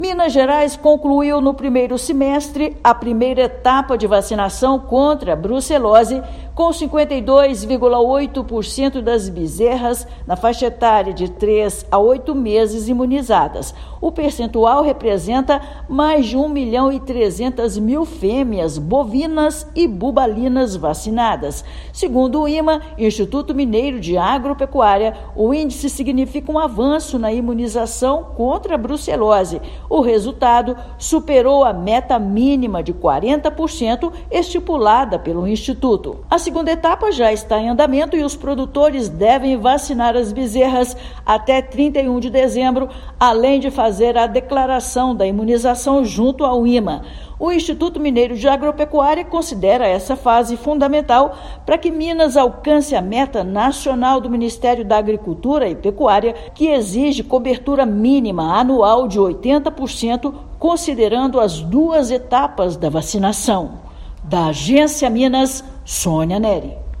Segunda fase já está em andamento e produtores têm até 31/12 para vacinar bezerras de 3 a 8 meses e declarar a imunização no IMA até 10/1/2026. Ouça matéria de rádio.